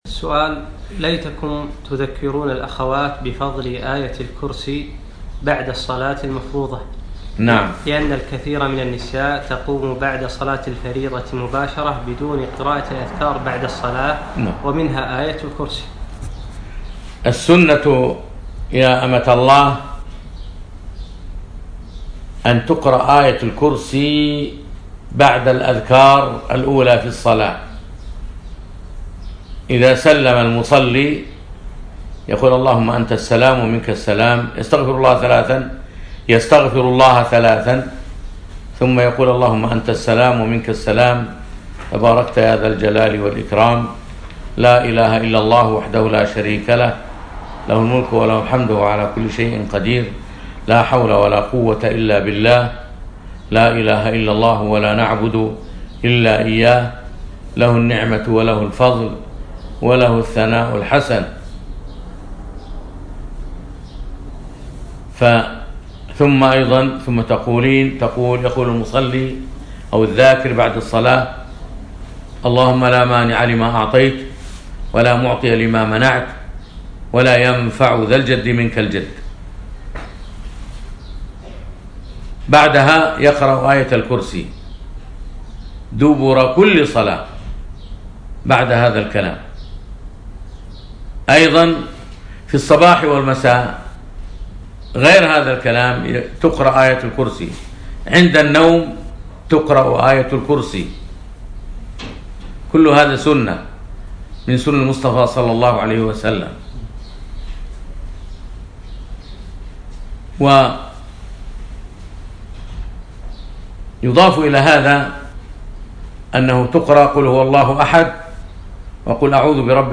يوم الأحد 5 رجب 1438 الموافق 2 4 2017 في أترجة الفحيحيل نسائي صباحي